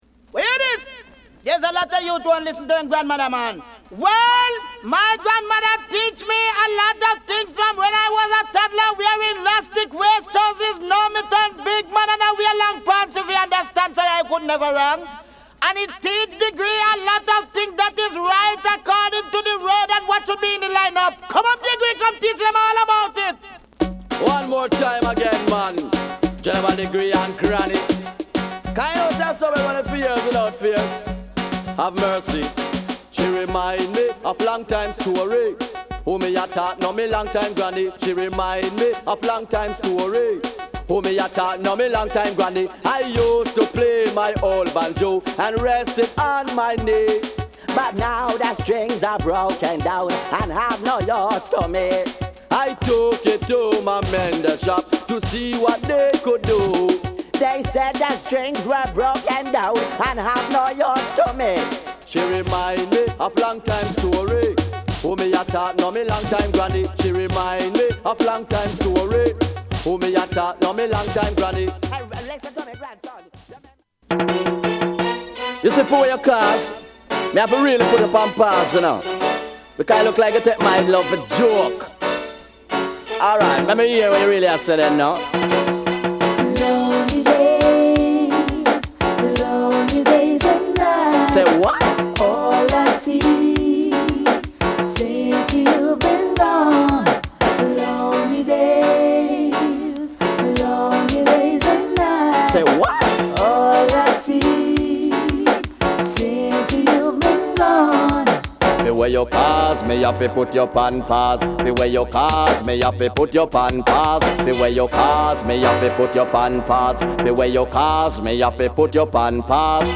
REGGAE / DANCEHALL